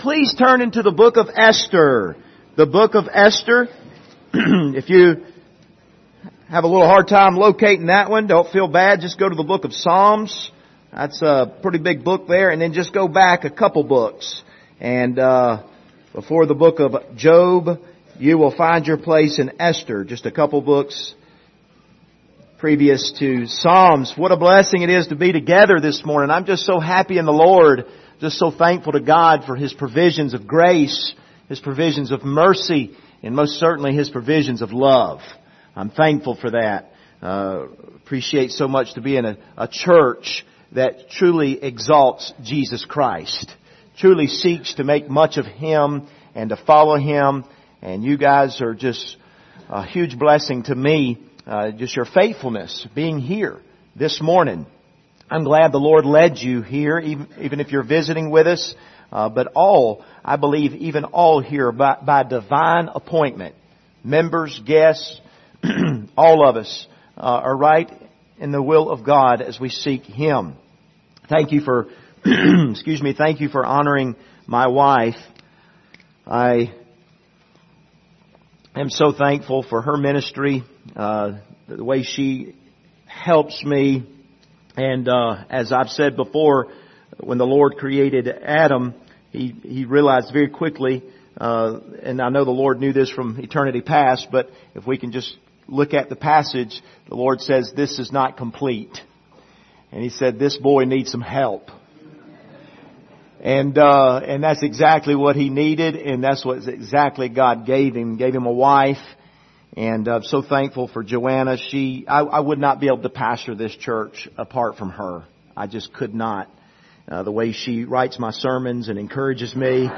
Passage: Esther 4:15 - 5:4 Service Type: Sunday Morning